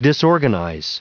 Prononciation du mot disorganize en anglais (fichier audio)
Prononciation du mot : disorganize